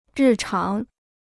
日常 (rì cháng) Free Chinese Dictionary